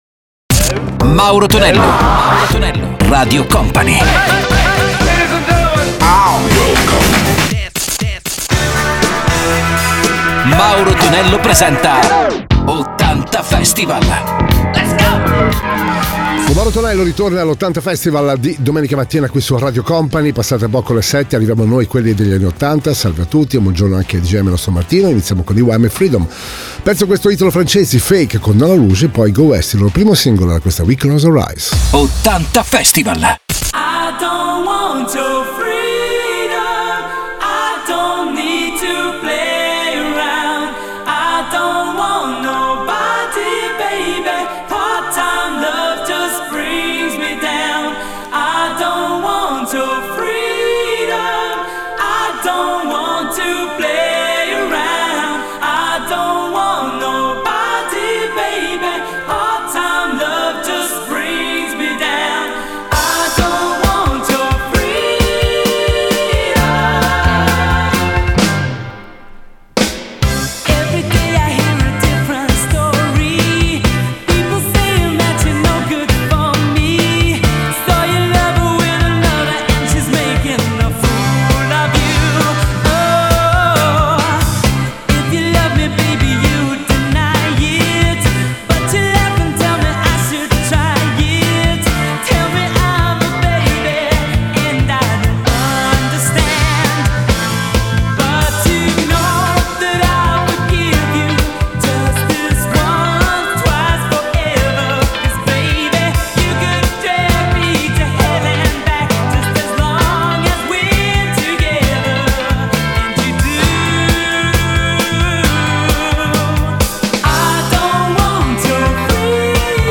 i maggiori successi degli anni 70 e 80